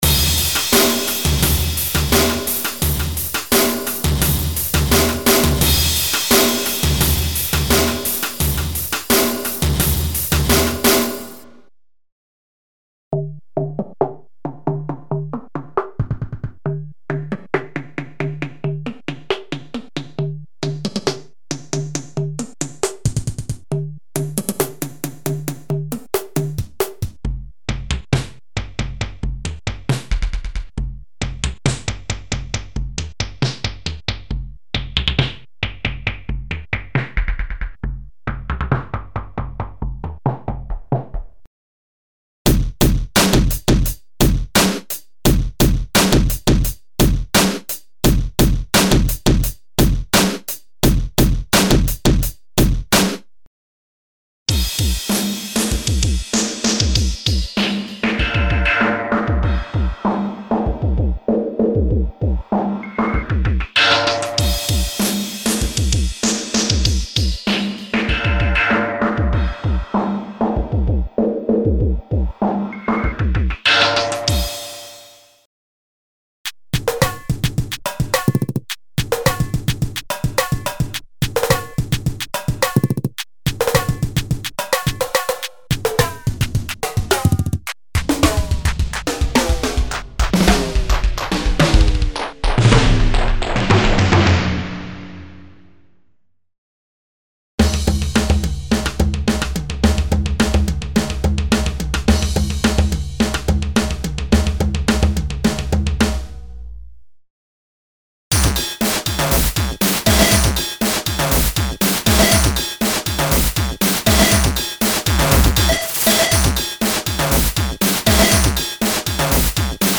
Acoustic and experimental drum & percussion kits created to take advantage of the special controller settings for internal DSP modulations (e.g. filter, pitch, shaper, distortion, etc.).
The collection is ideal for a wide variery of music styles, ranging from Jazz and Rock to Hip-Hop and underground electronic music.
Info: All original K:Works sound programs use internal Kurzweil K2600 ROM samples exclusively, there are no external samples used.
K-Works - Drums & Percussion Volume 2 - LE (Kurzweil K2xxx).mp3